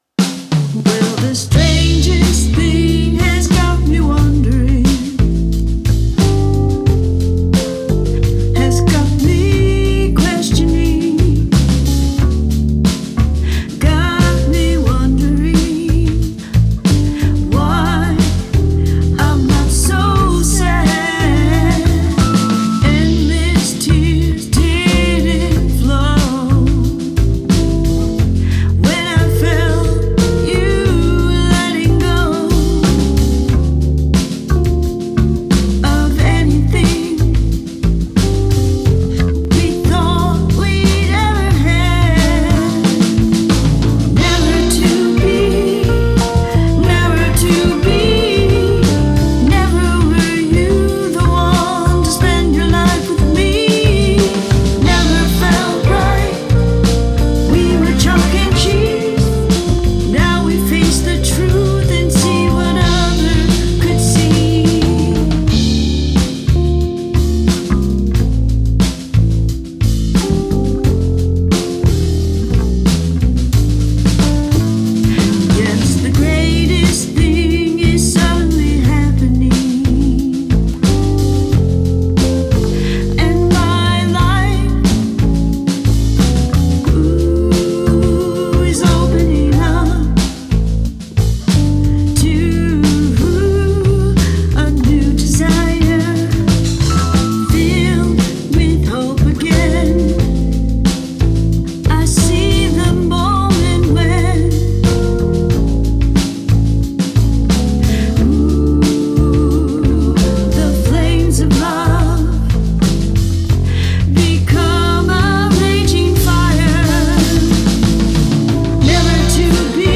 Pop Ballad